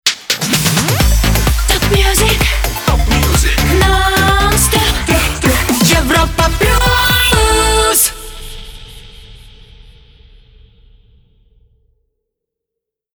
05 Jingle